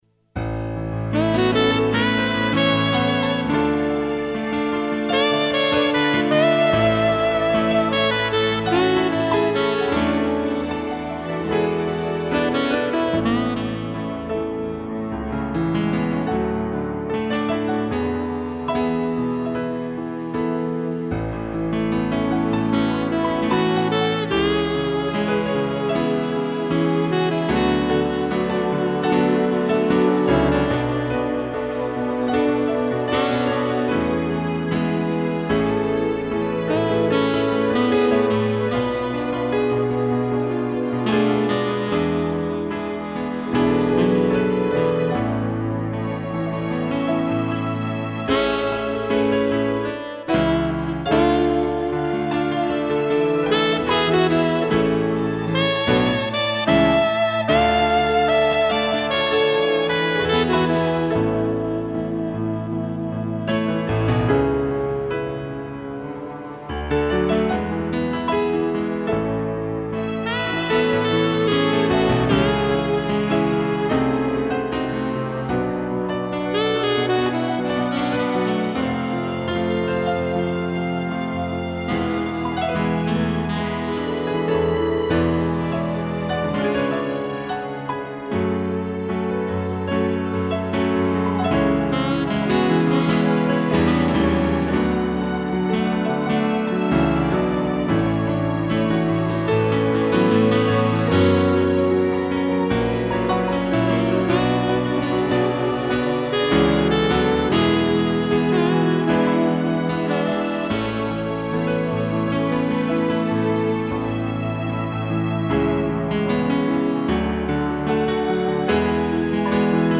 2 pianos, sax e cello
(instrumental)